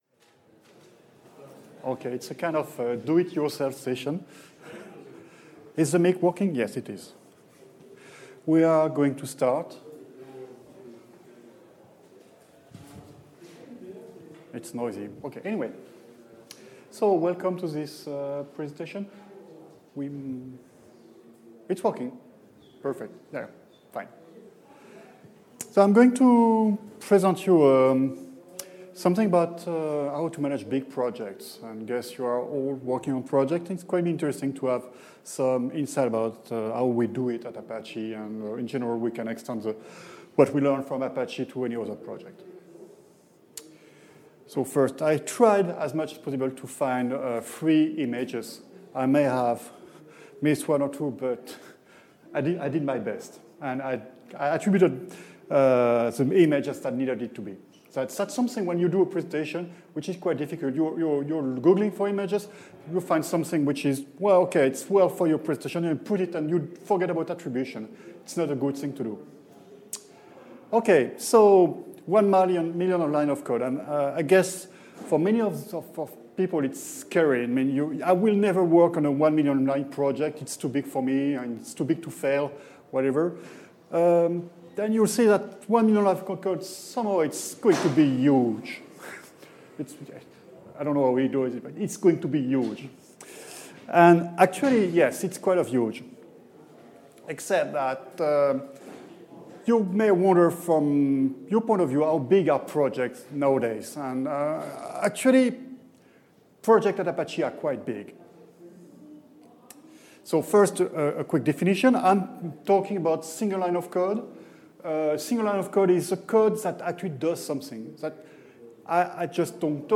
ApacheCon Seville 2016